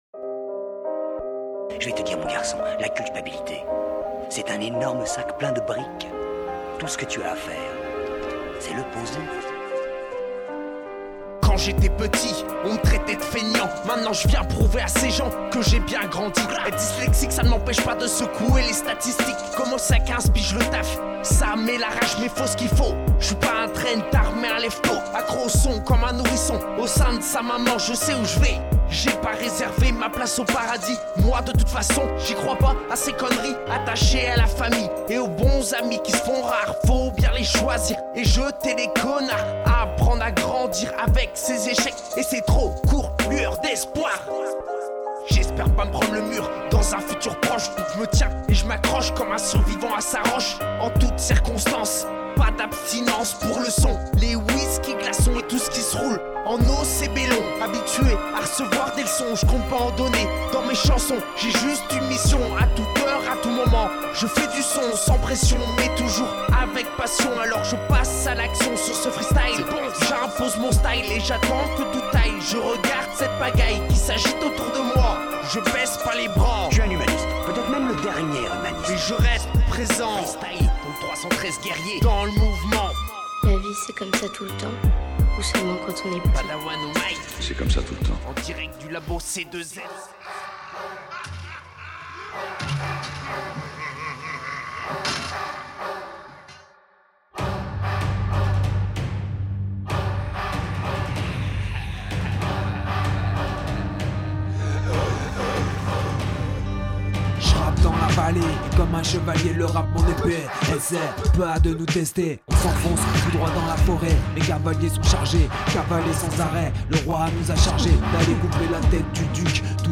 6 mixed tracks